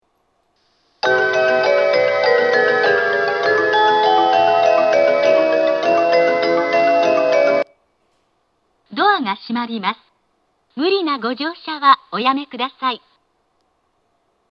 上り発車メロディー
0.7コーラスです。